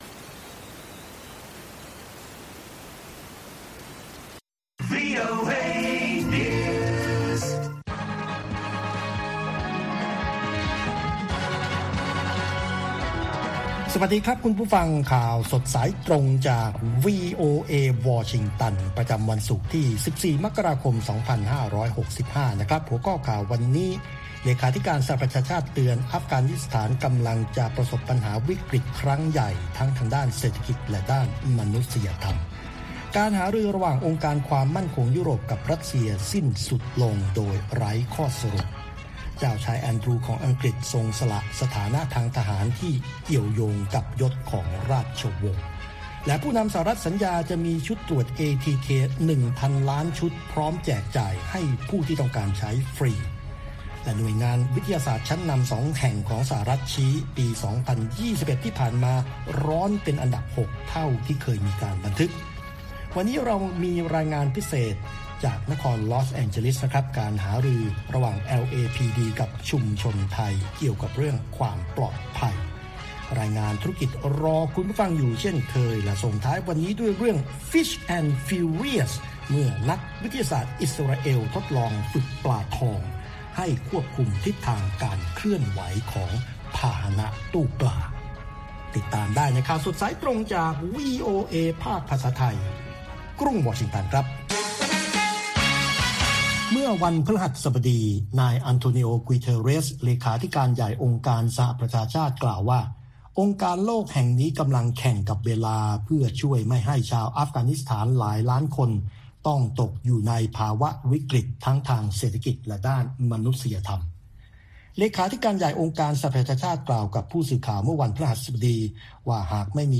ข่าวสดสายตรงจากวีโอเอ ภาคภาษาไทย ประจำวันศุกร์ที่ 14 มกราคม 2565 ตามเวลาประเทศไทย